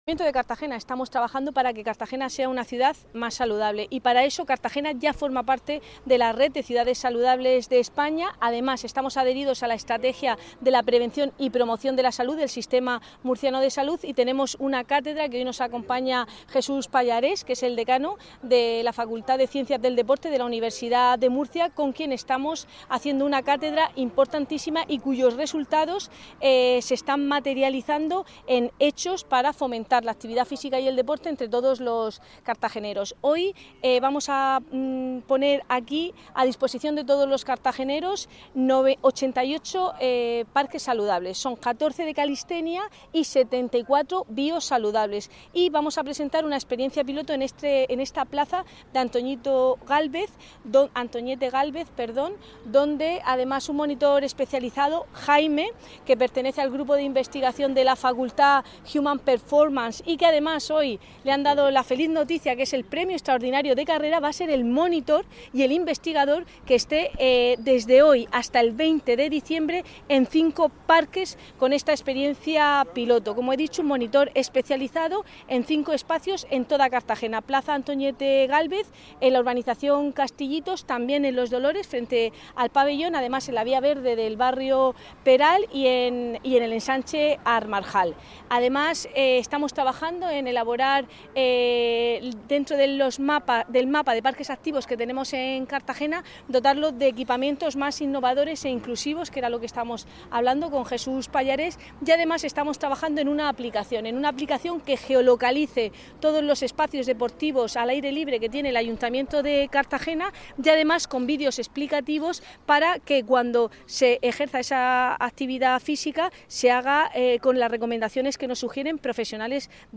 Enlace a Declaraciones de la edil Cristina Mora